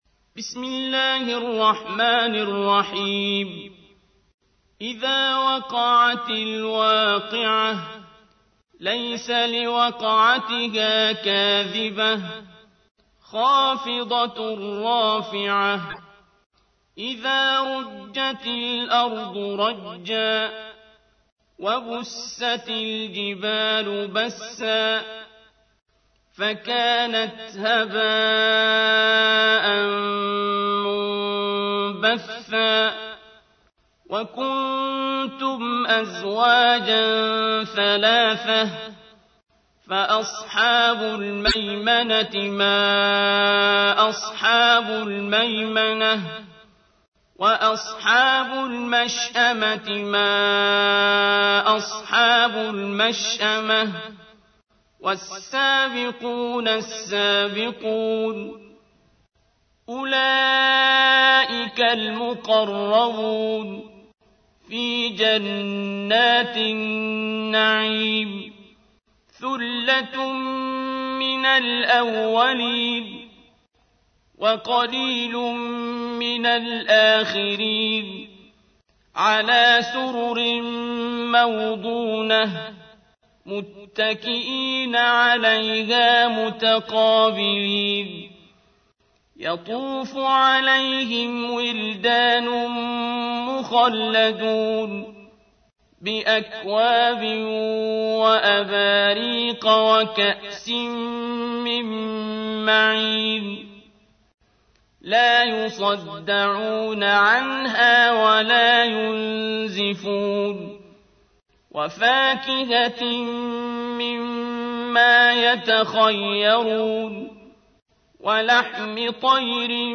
تحميل : 56. سورة الواقعة / القارئ عبد الباسط عبد الصمد / القرآن الكريم / موقع يا حسين